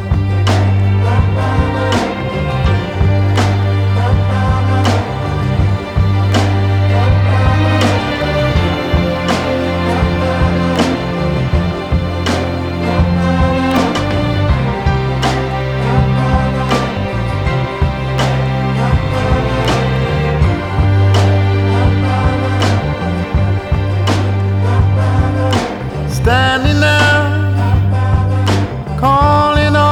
• Soul